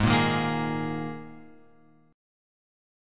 G# Suspended 2nd
G#sus2 chord {x x 6 3 4 4} chord